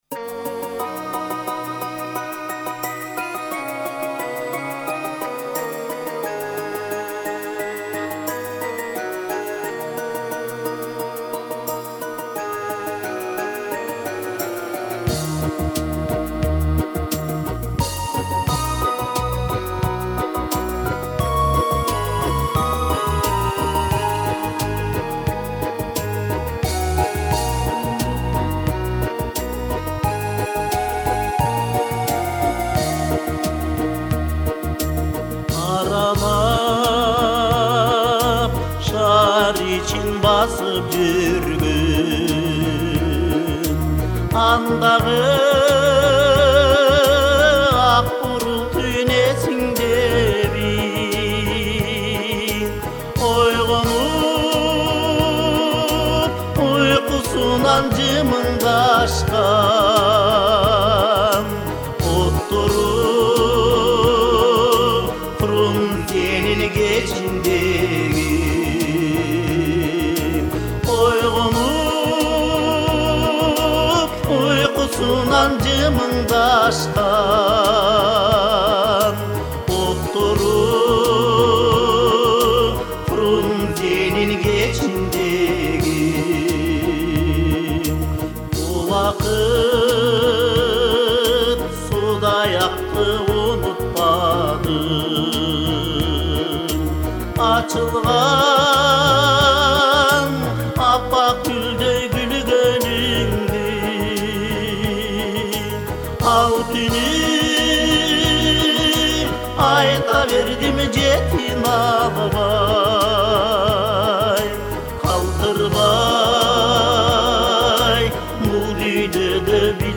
КЫРГЫЗЧА ЖАГЫМДУУ ЖАҢЫ ЫРЛАР
кыргызча ырлар